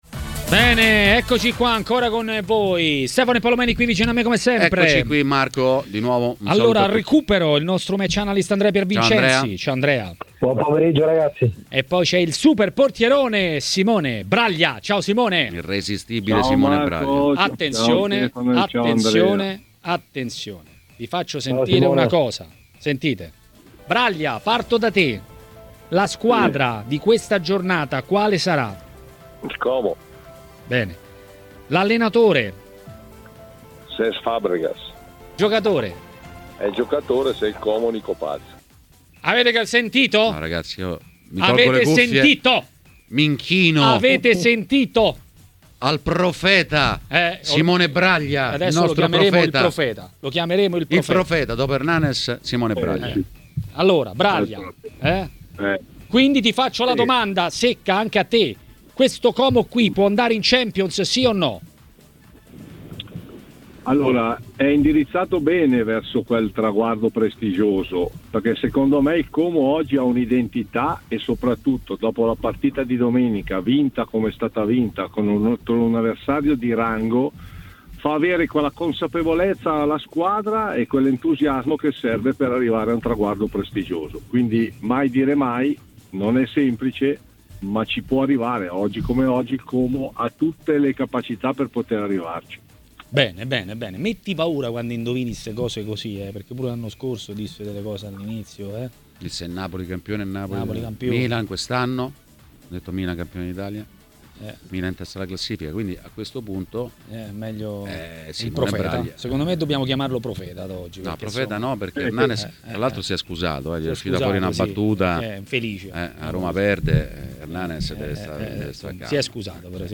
L'ex portiere Simone Braglia è stato ospite di Maracanà, trasmissione del pomeriggio di TMW Radio.